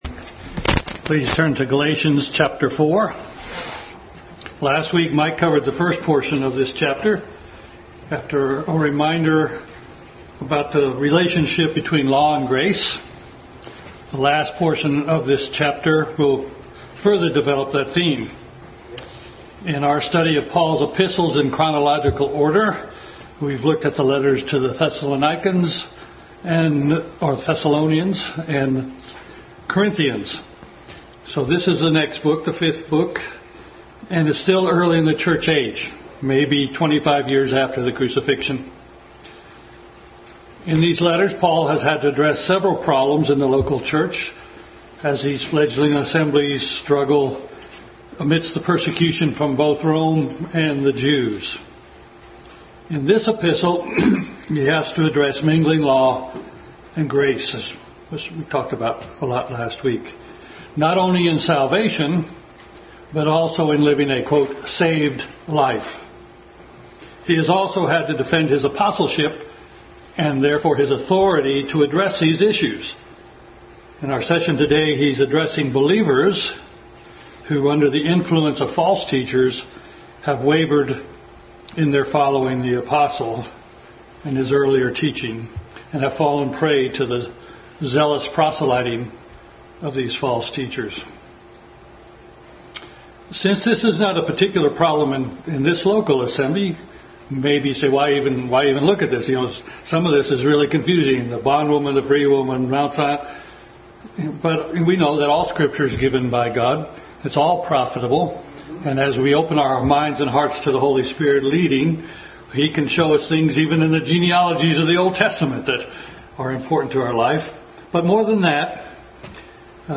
Adult Sunday School Class continued Study in Galatians.